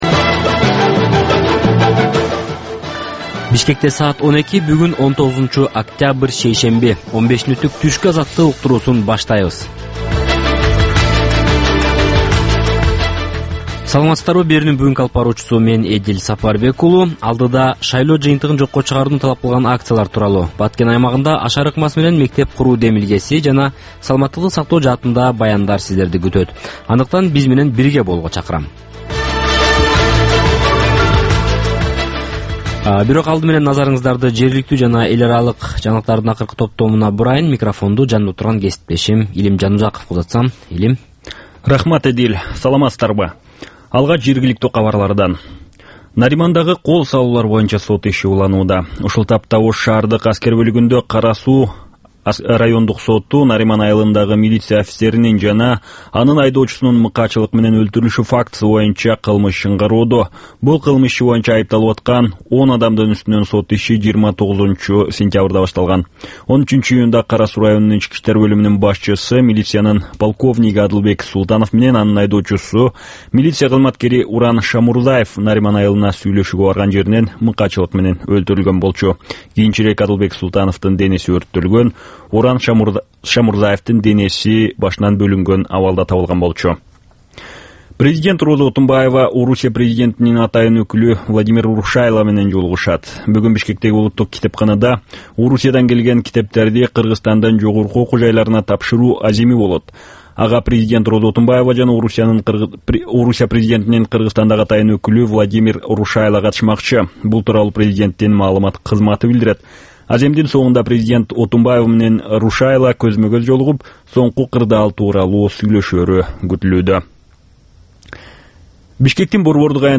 Чак түштөгү кабарлар